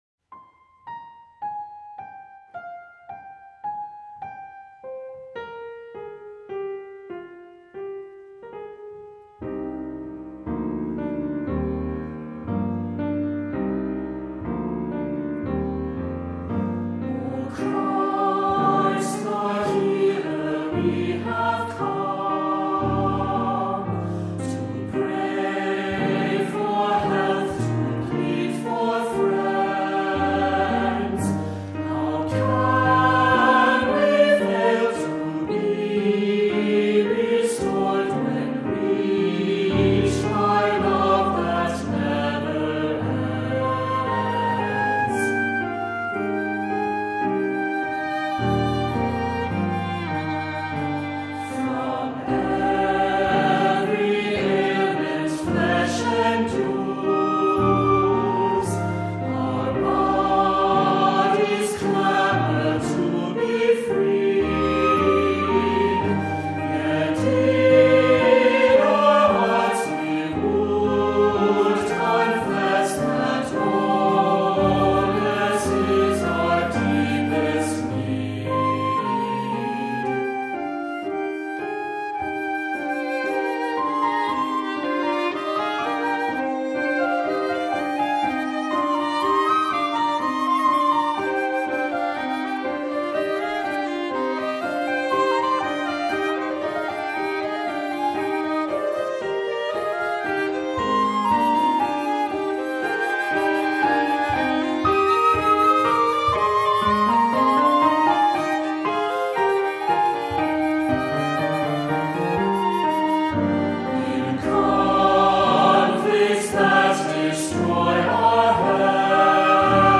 Voicing: Unison; Descant